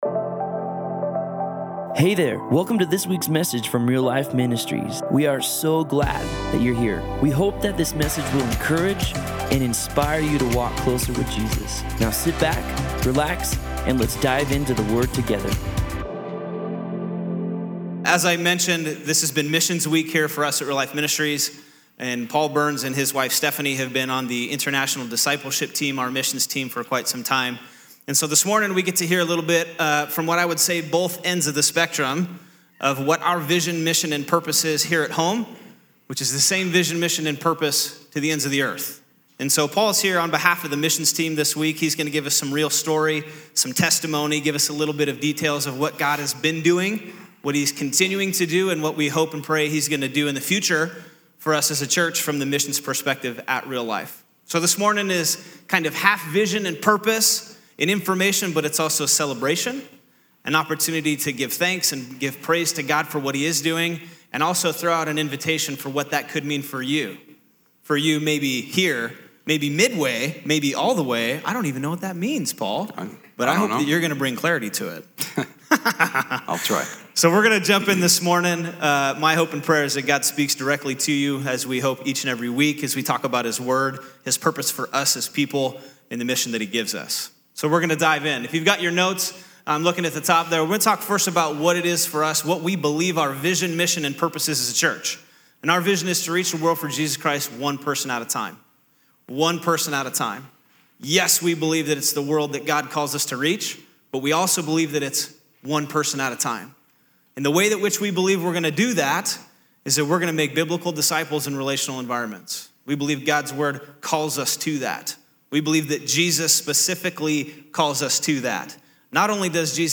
Missions Week- CD'A Campus
Sermon